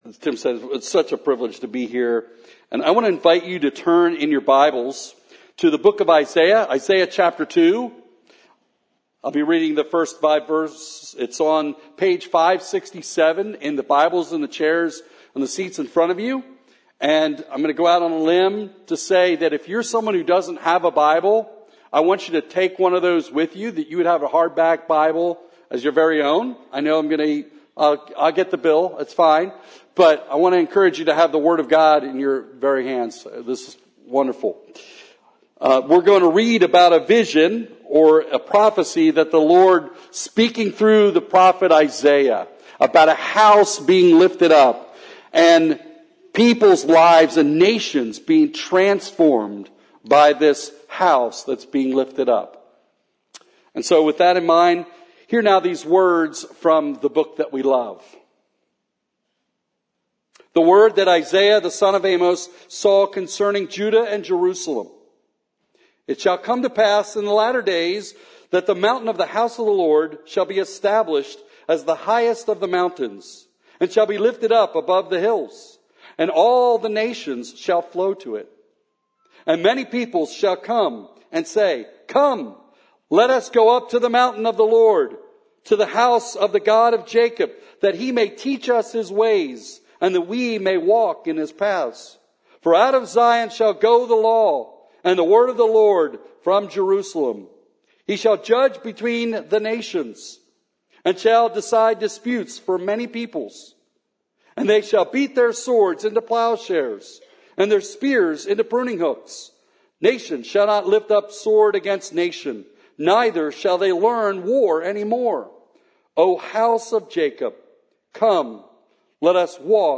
A message from the series "50th Anniversary Sermons." Paul bids Timothy farewell and reminds him (and us!) to found our faith on the Christ of Scripture.